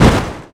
hitwall.mp3